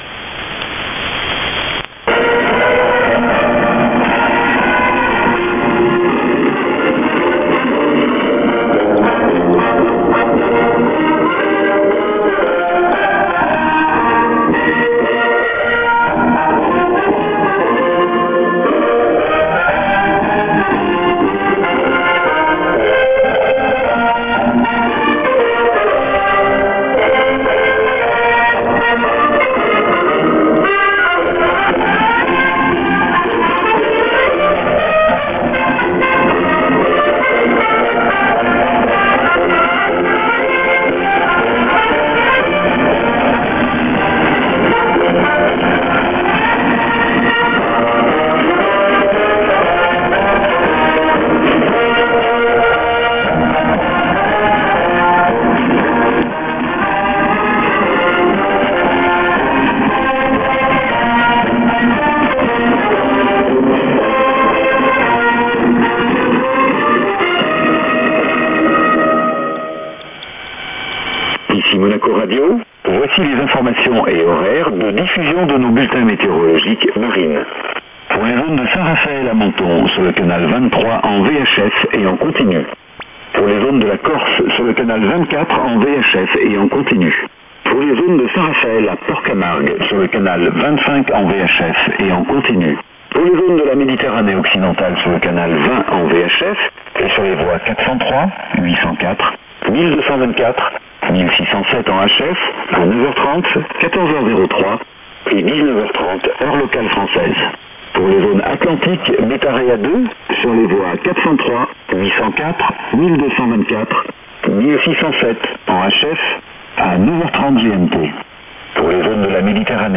a surprising weather show transmission, maybe next in stereo...?